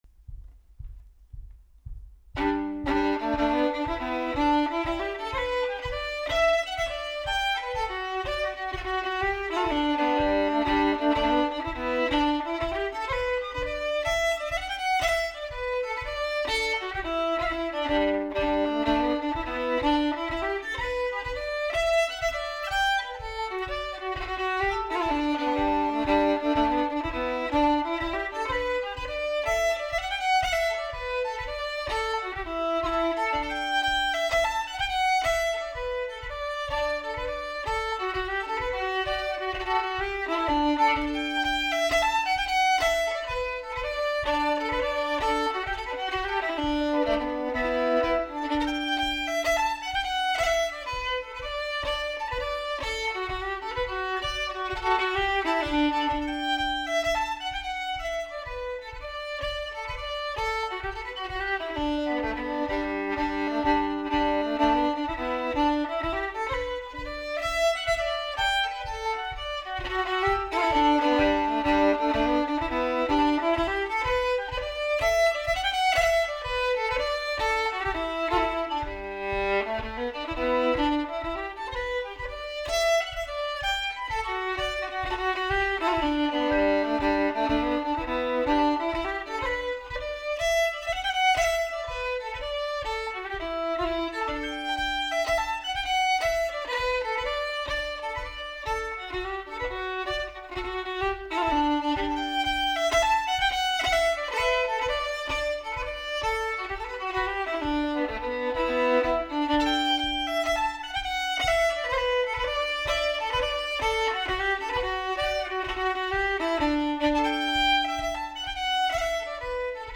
Trip to Moultonborough | The Séamus Connolly Collection of Irish Music